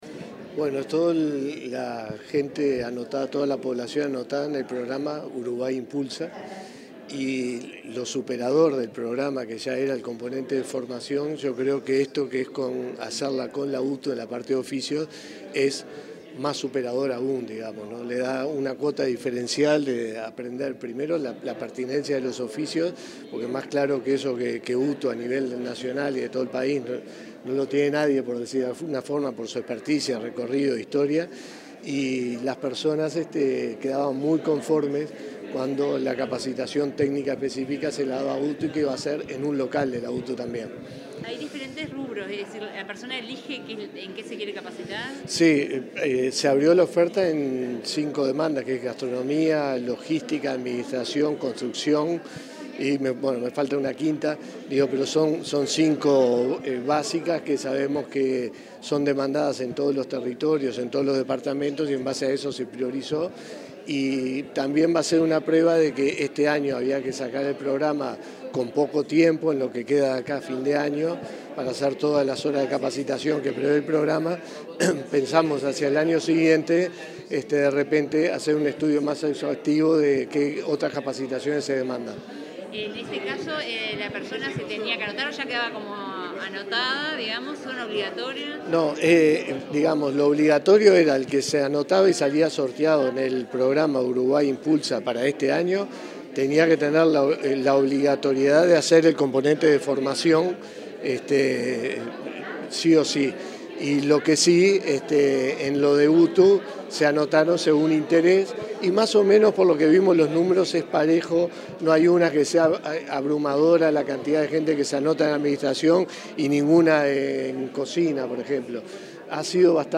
Declaraciones del director general de Inefop, Miguel Venturiello
En la presentación de los cursos técnicos de Uruguay Impulsa, el director general del Instituto Nacional de Empleo y Formación Profesional (Inefop),